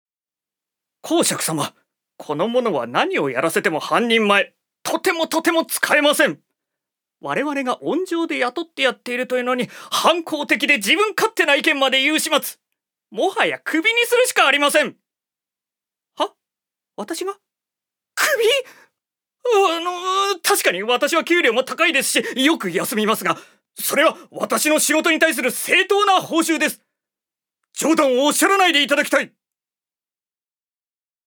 所属：男性タレント
セリフ２